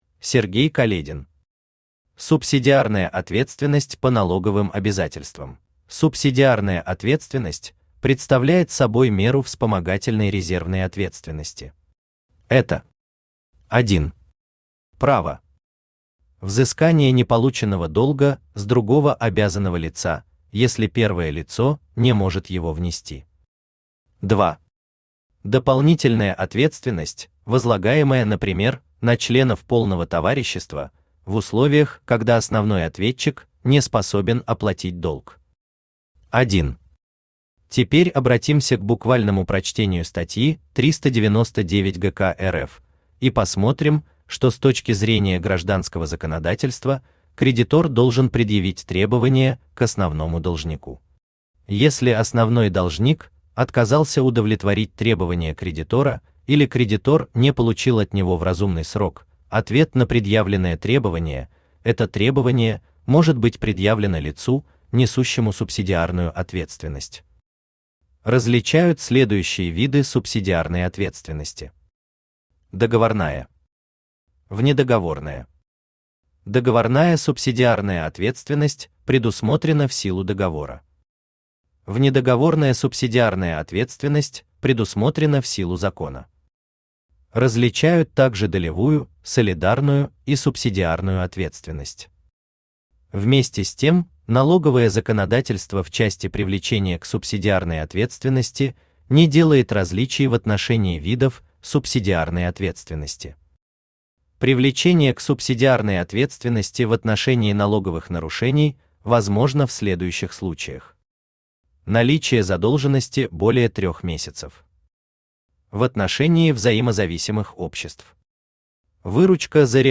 Aудиокнига Субсидиарная ответственность по налоговым обязательствам Автор Сергей Каледин Читает аудиокнигу Авточтец ЛитРес.